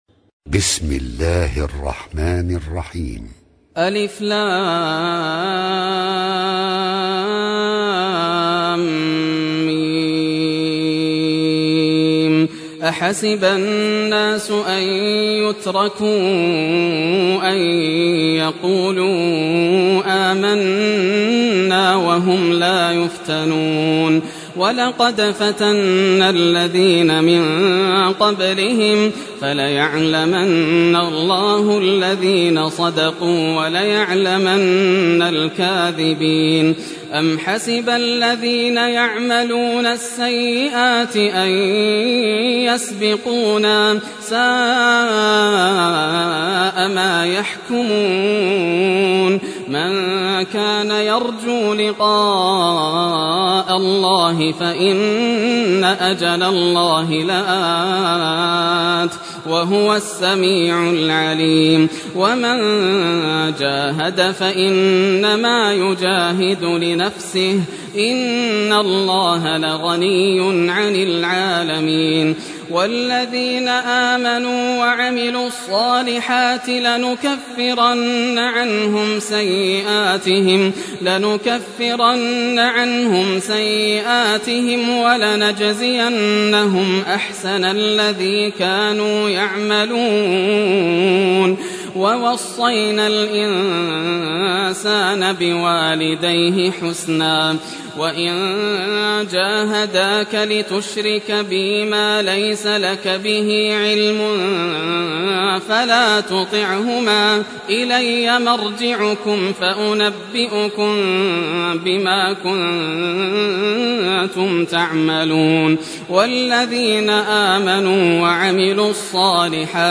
Surah Al-Ankabut Recitation by Yasser al Dosari
Surah Al-Ankabut, listen or play online mp3 tilawat / recitation in Arabic in the beautiful voice of Sheikh Yasser al Dosari.